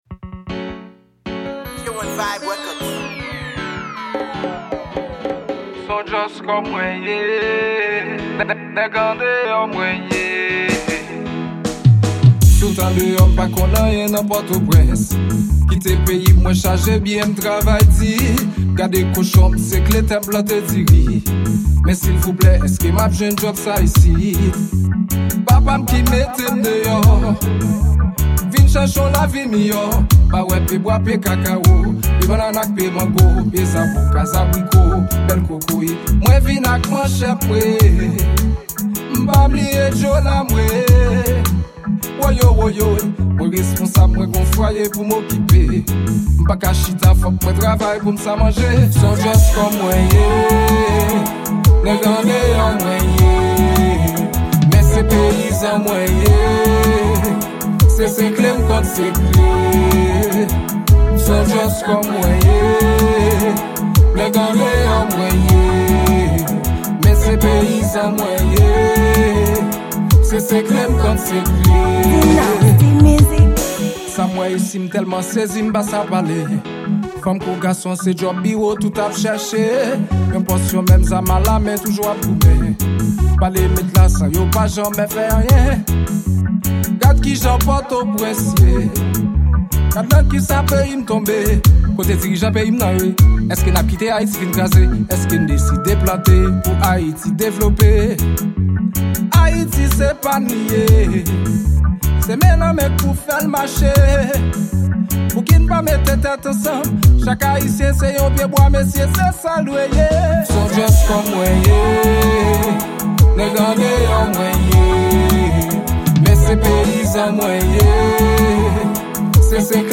Genre: Reggae